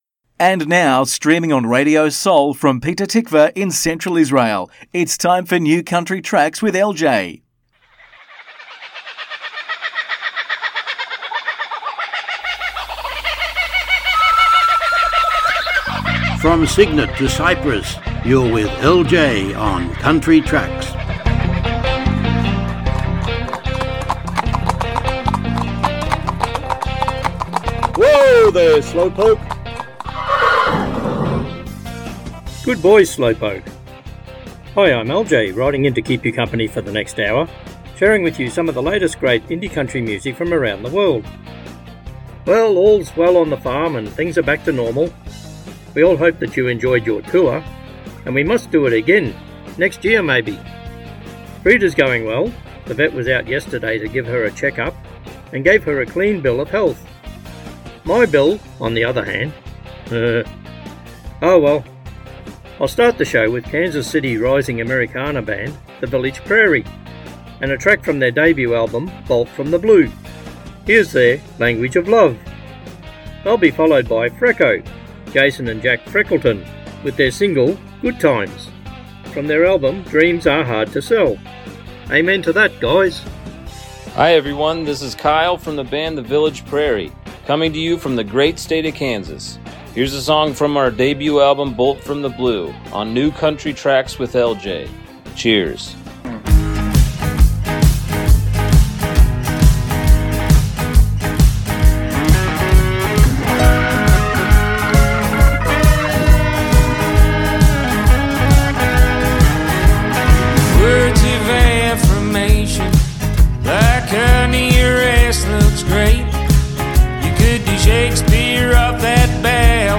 מוזיקת קאנטרי ואינדי עולמית - התכנית המלאה 31.1.25